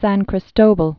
(săn krĭs-tōbəl, sän krē-stōväl)